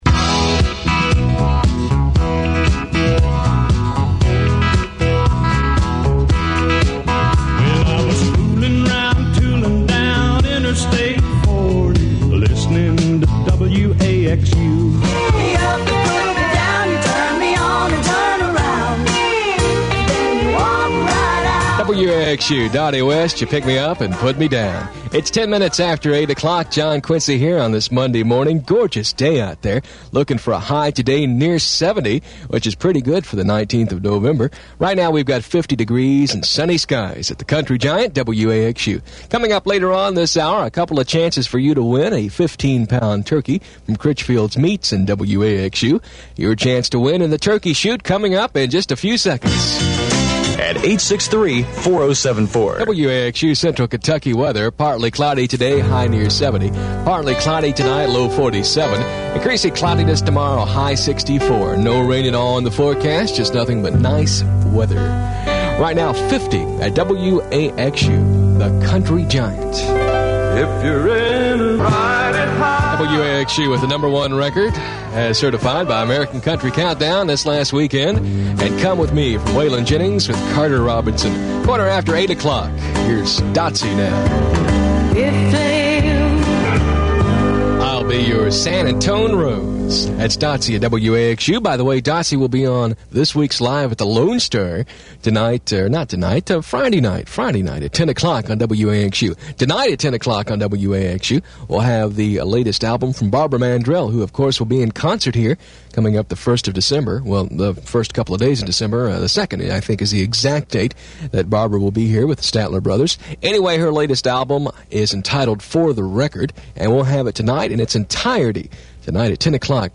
WAXU-FM had gone stereo since I had left the first time, so a McMillan stereo console replaced the old mono Collins board in the control room. Spots and jingles were still played from the mono ITC triple decker, and music still came from turntables.
The microphone reverb had been retired.
WAXU Airchecks